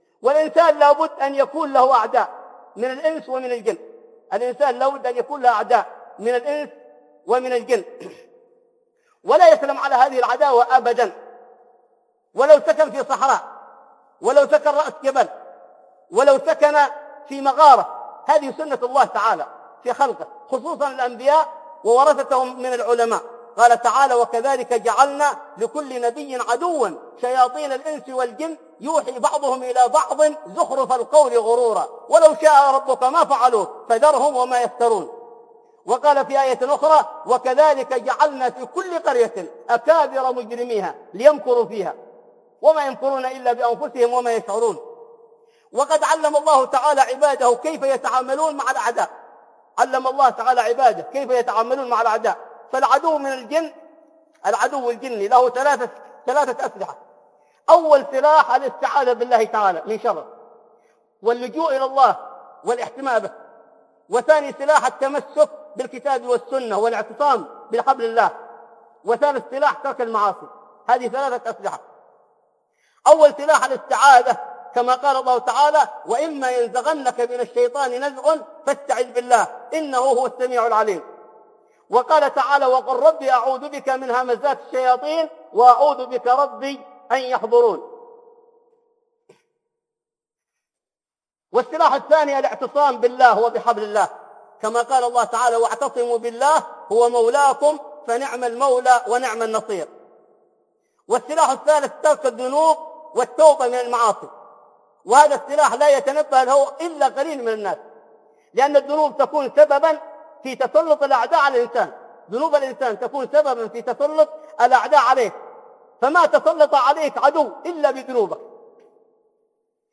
كيفية التعامل مع الأعداء من الجن والإنس - خطب